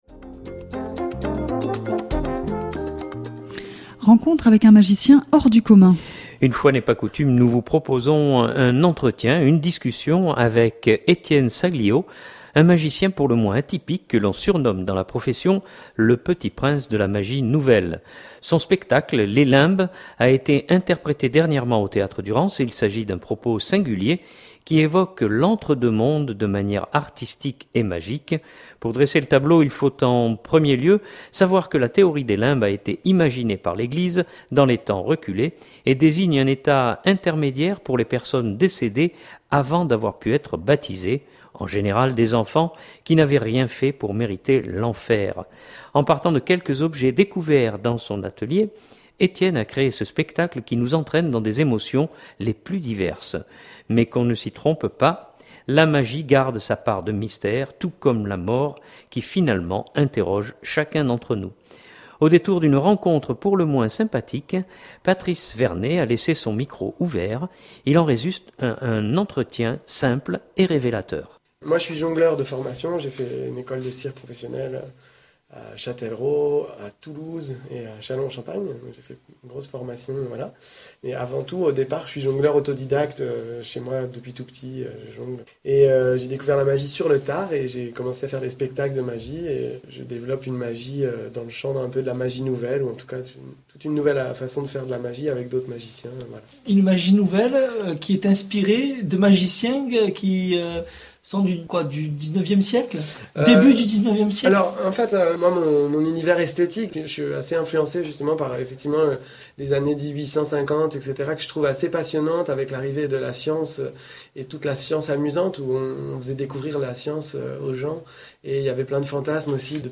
Il en résulte un entretien simple et révélateur.